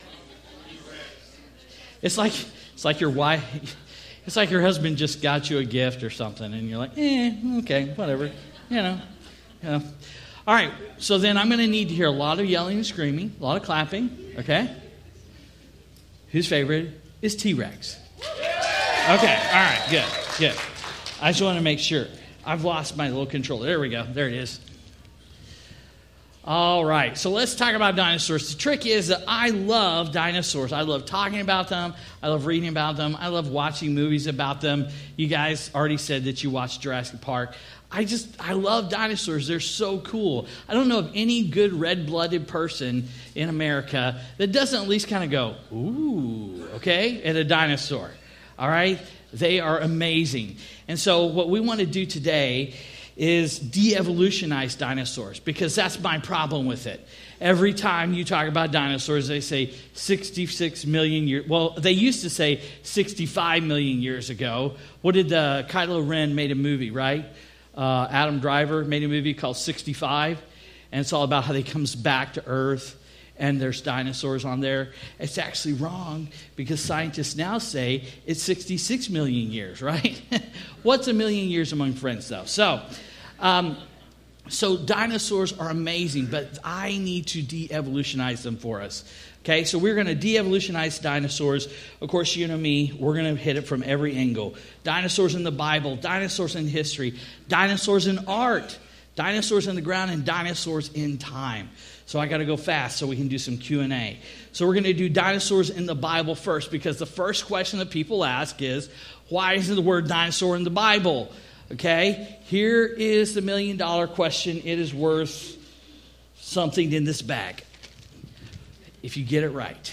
Institute For Creation Research Conference -Session Four